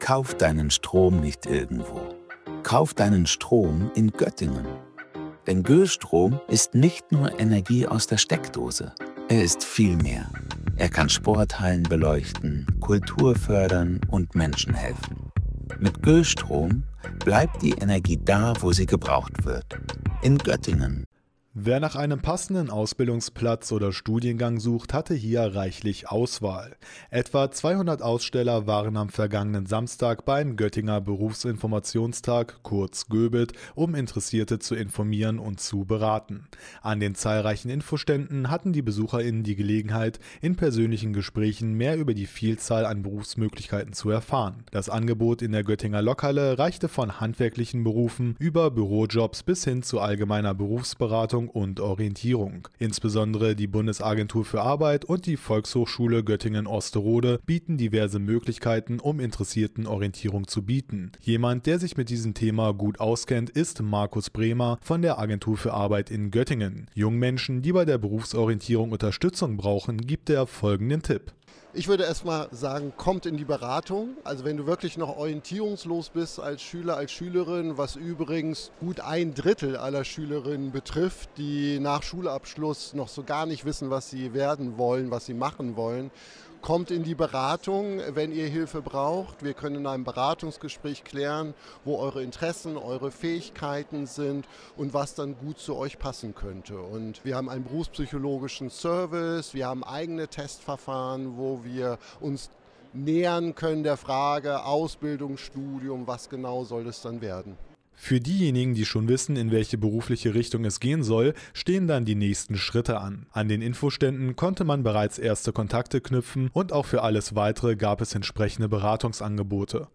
Für sie und viele weitere war der GöBit genau der richtige Ort, um mehr über die Vielzahl an Berufsmöglichkeiten und entsprechende Beratungsangebote zu erfahren. Das StadtRadio hat den Aktionstag mit einer Sondersendung begleitet.